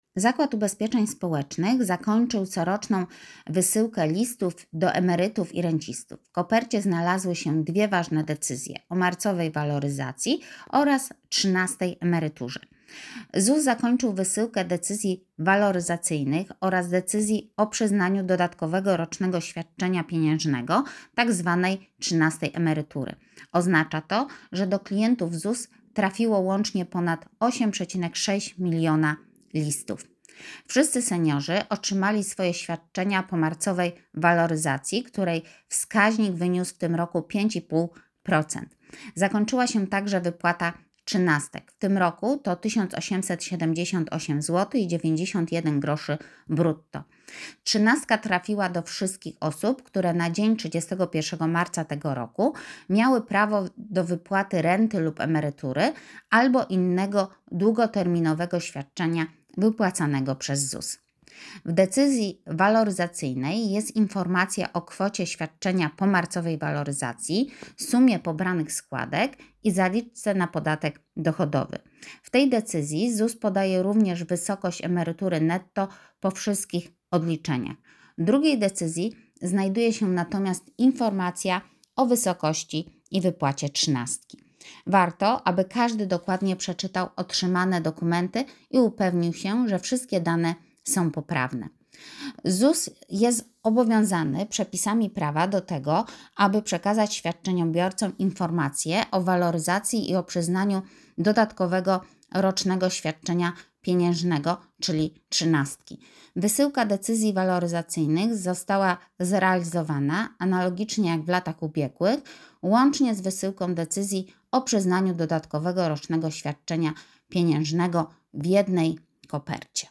Alternatywne wersje artykułu Ten artykuł jest dostępny również w innych formatach: Pobierz artykuł w wersji edytowalnej (plik DOCX, 42 KB) Pobierz artykuł w wersji dzwiękowej z lektorem (plik M4A, 1,8 MB)